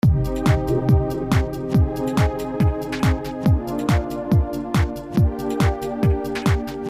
sirena
sirena.mp3